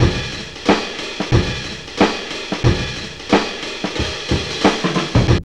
JAZZLP5 91.wav